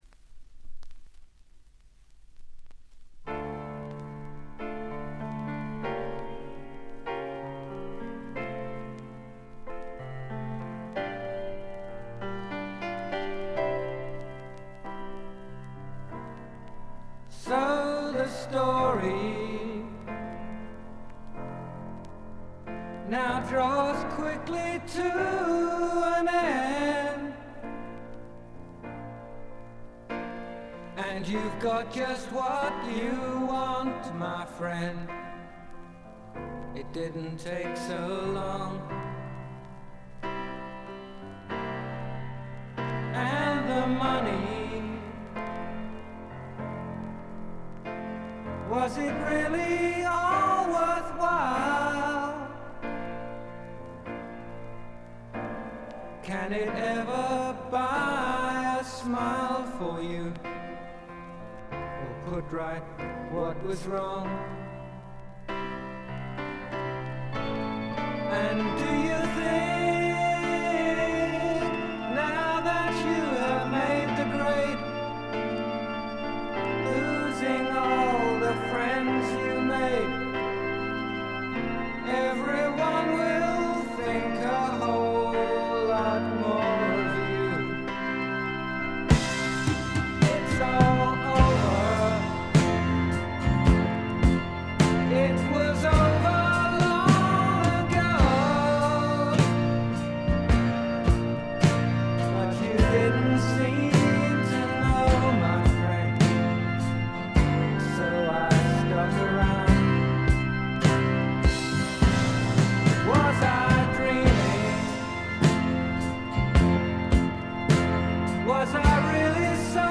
静音部で少軽いバックグラウンドノイズ程度。
試聴曲は現品からの取り込み音源です。